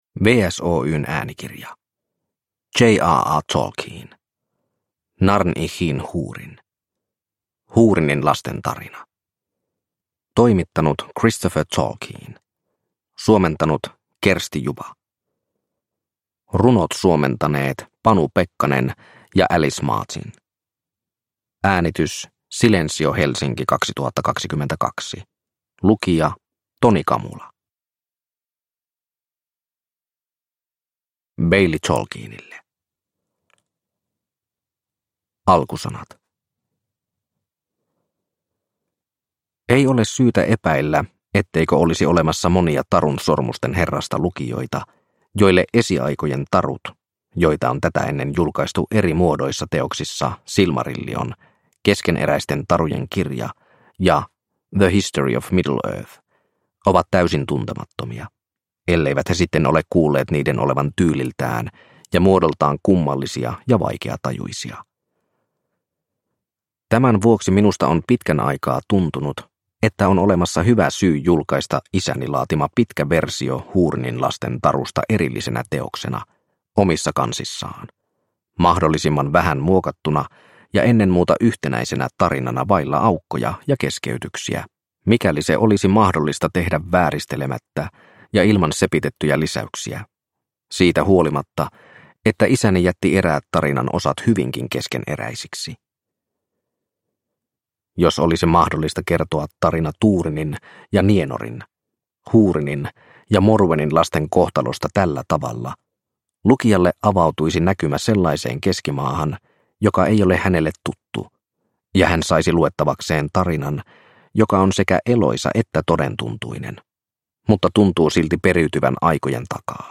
Húrinin lasten tarina – Ljudbok – Laddas ner